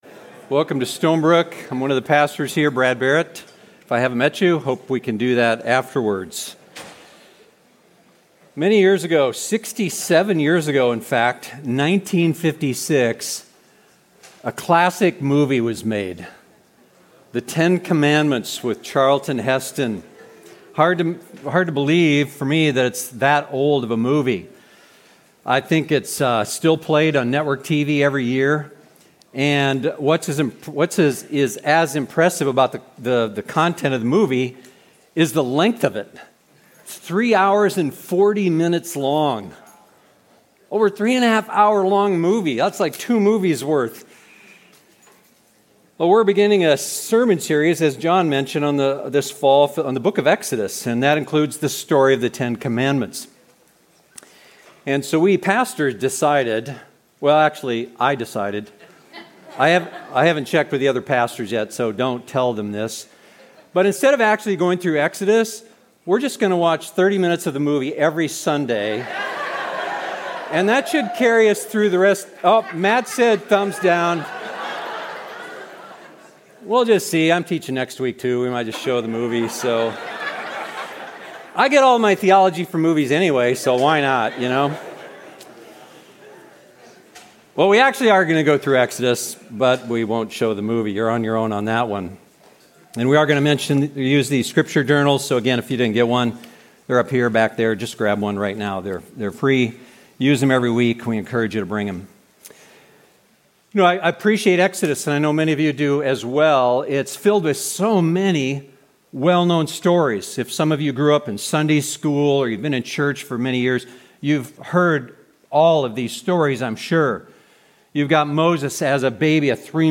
We are beginning a sermon series for the Fall on the Book of Exodus. Exodus is filled with many well-known stories: These are all remarkable, inspiring stories that tell us who God is, and we see examples of both faith and unbelief.